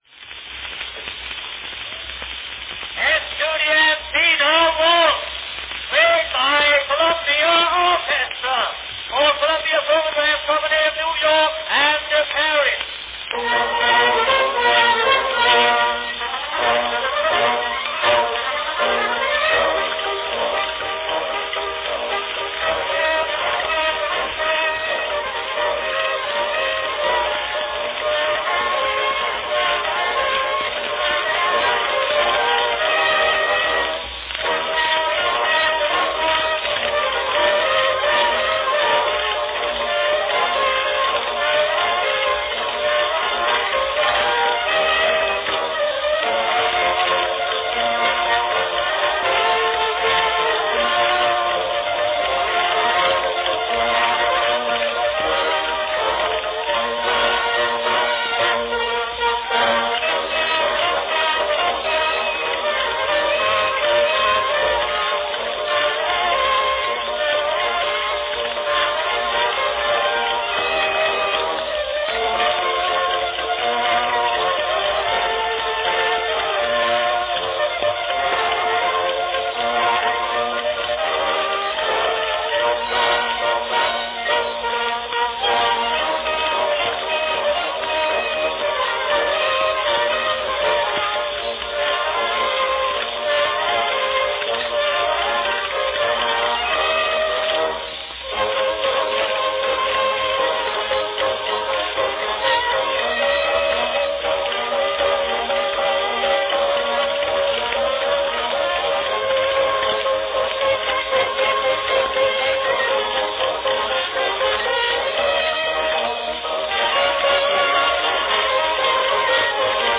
An early recording of the popular Estudiantina Waltz from 1898.
Category Orchestra
Performed by Columbia Orchestra
Announcement "Estudiantina Waltz, played by Columbia Orchestra for Columbia Phonograph Company of New York and Paris."